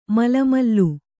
♪ mala mallu